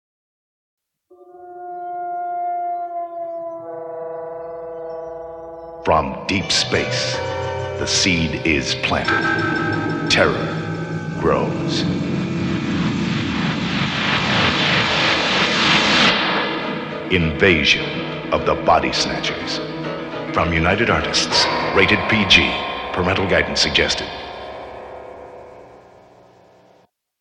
Radio Spots
Here are six spots for the 1978 release…three mono spots and three spots in stereo.